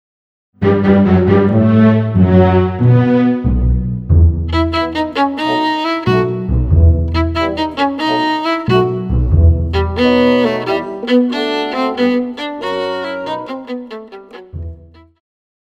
古典
中提琴
樂團
演奏曲
僅伴奏
沒有主奏
沒有節拍器